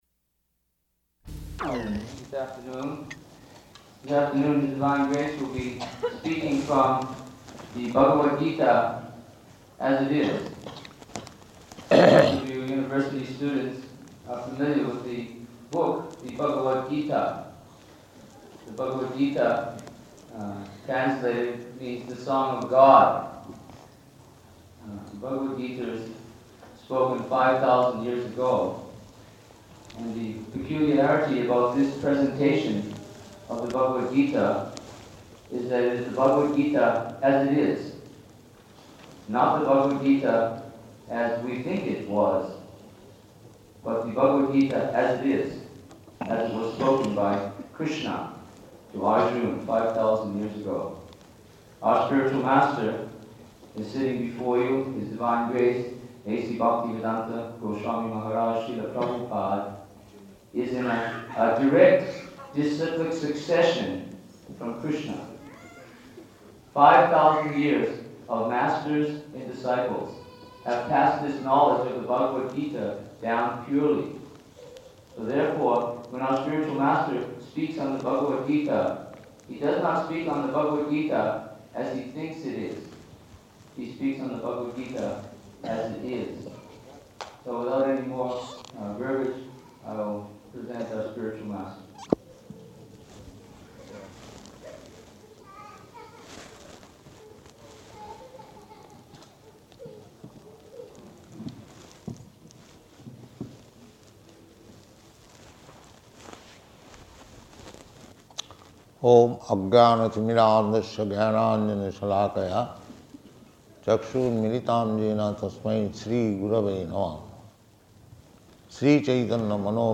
Type: Lectures and Addresses
Location: Melbourne